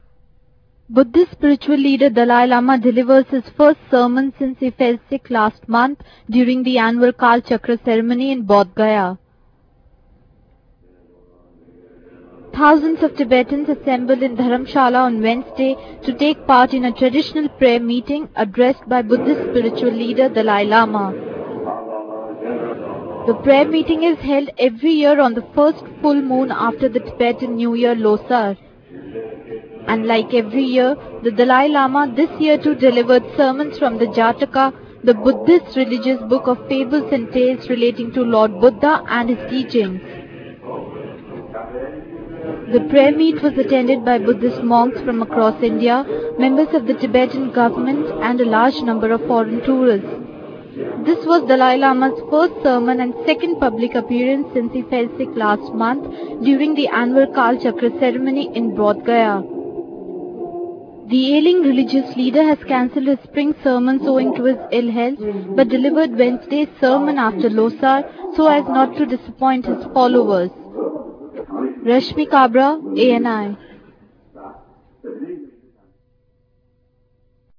Buddhist spiritual leader Dalai Lama delivers his first sermon since he fell sick last month during the annual Kal Chakra ceremony in Bodhgaya.